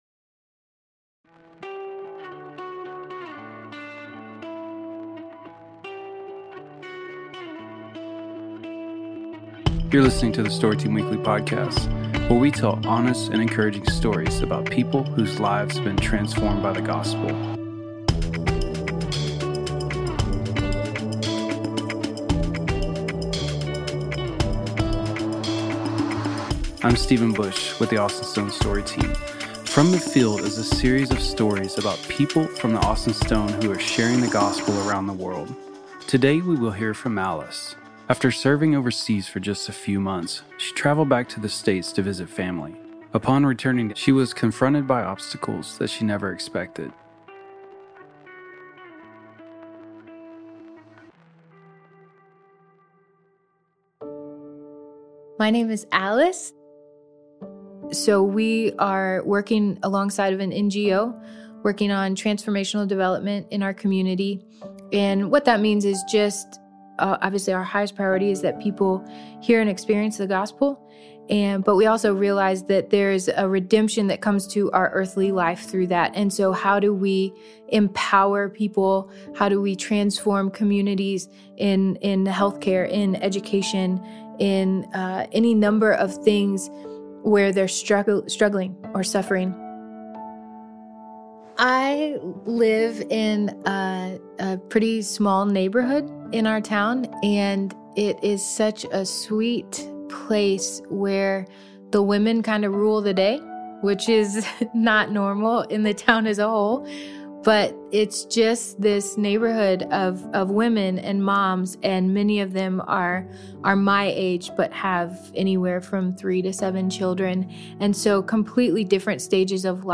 Download - Joy (Advent Spoken Word) | Podbean